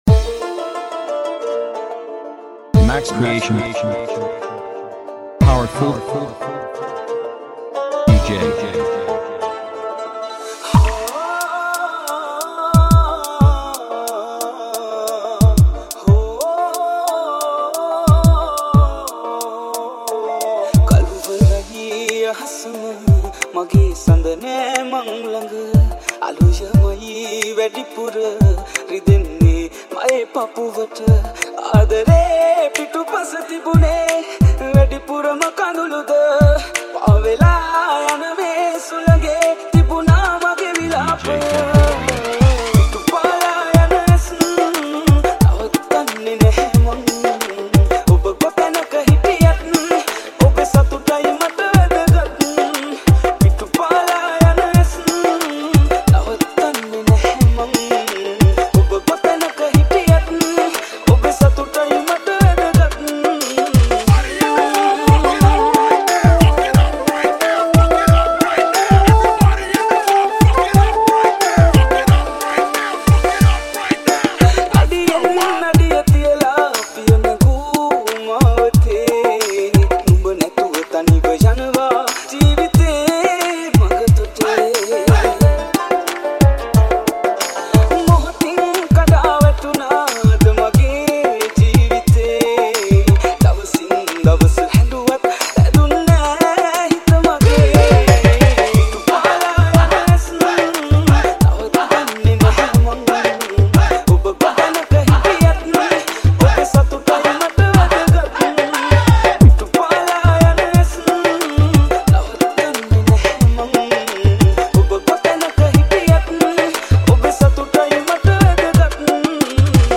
Sri Lankan remix